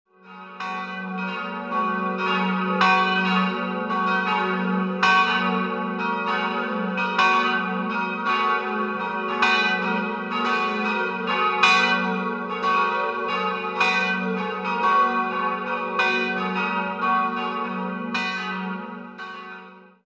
Sie wurde im Jahr 1738 nach Plänen des wallersteinischen Baumeisters Johann Georg Conradi im Markgrafenstil erbaut. 3-stimmiges Paternoster-Geläut: fis'-gis'-ais' Die Glocken wurden 1950 von Friedrich Wilhelm Schilling in Heidelberg gegossen.